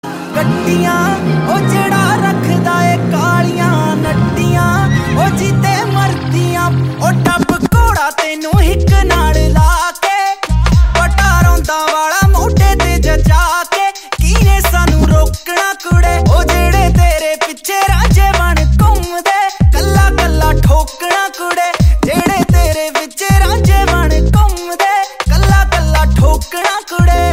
Punjabi Ringtones